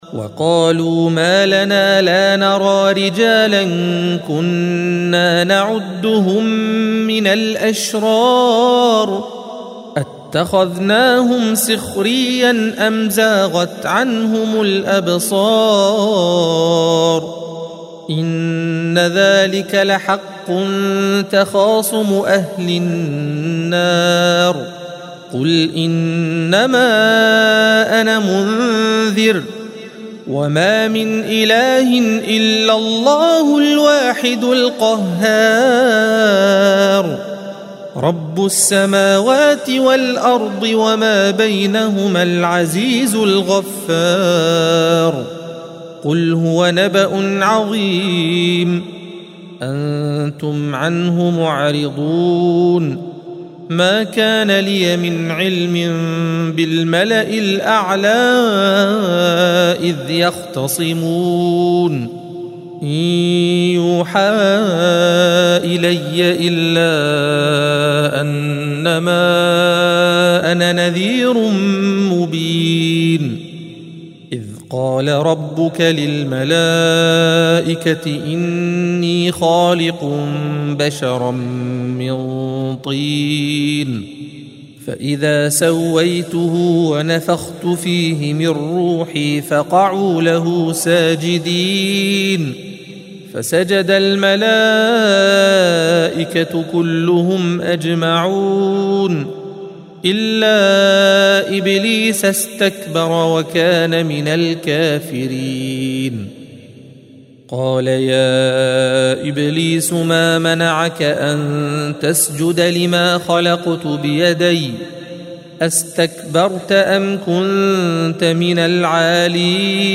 الصفحة 457 - القارئ